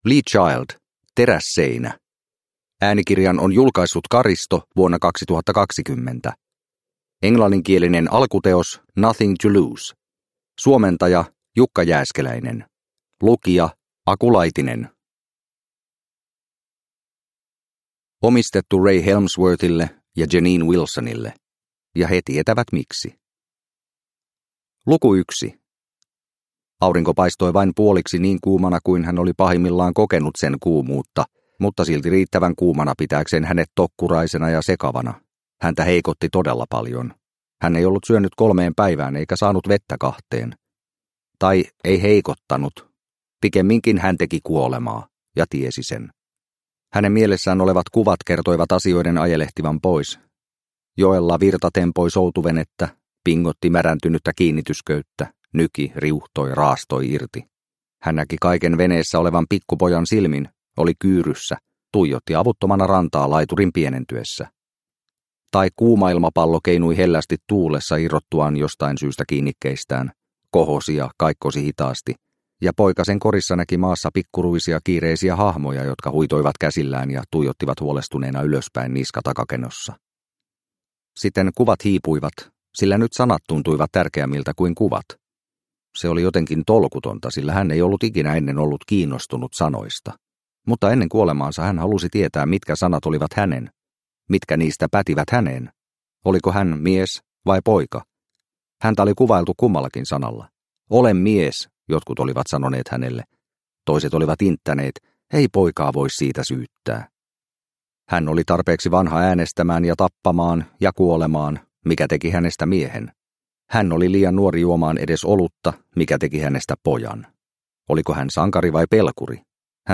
Terässeinä – Ljudbok – Laddas ner